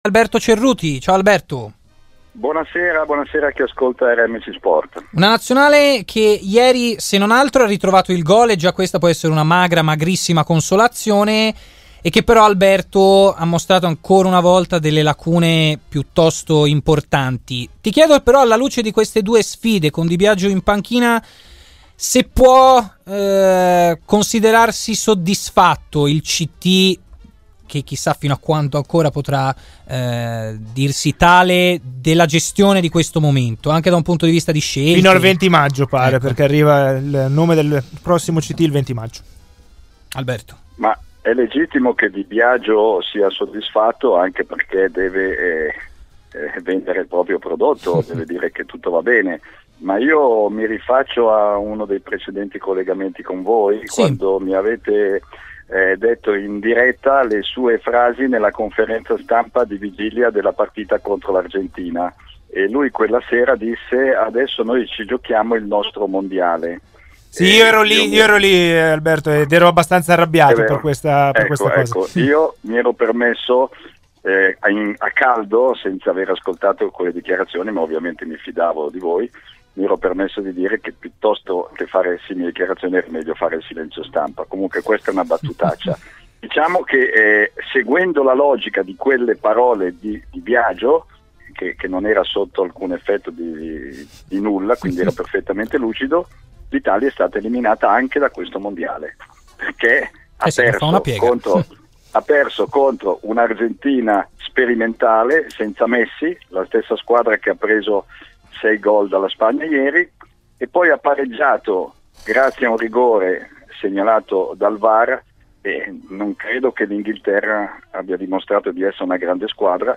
nel suo intervento all'interno del Live Show ha parlato così della Nazionale italiana
In studio